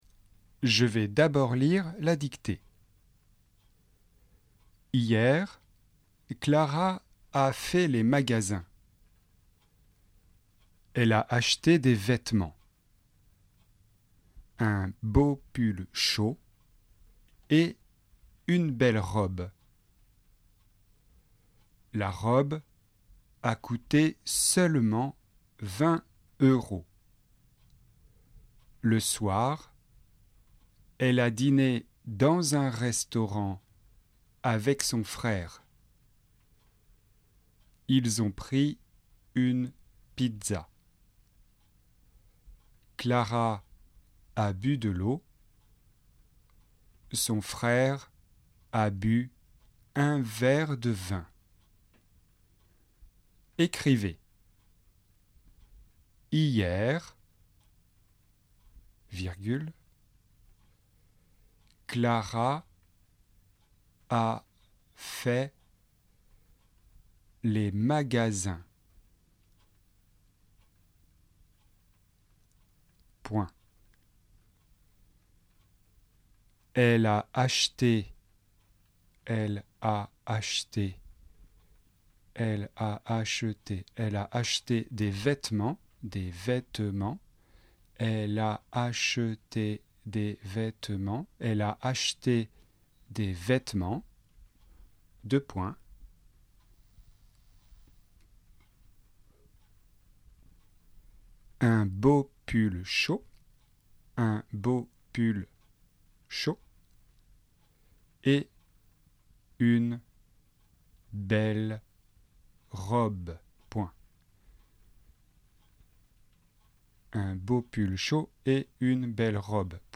Dictée 12 : passé composé (avoir)